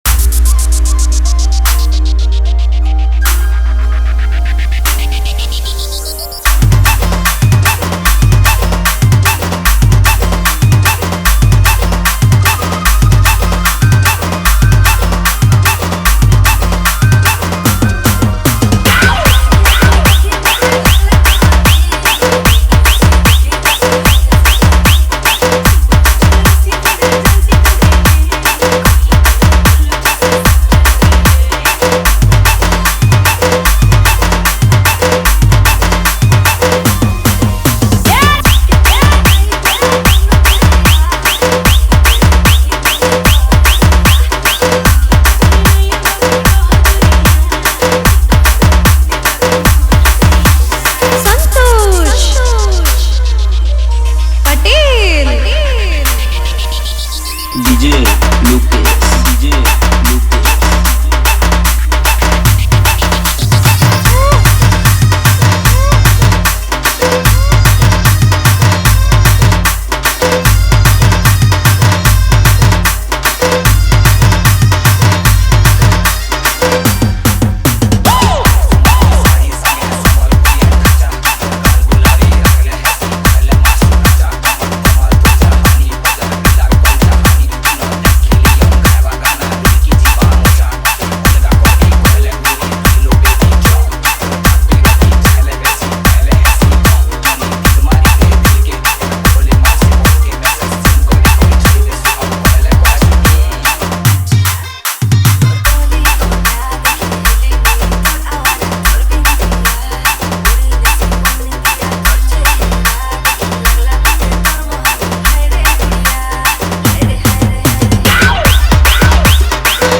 Category:  Sambalpuri Dj Song 2022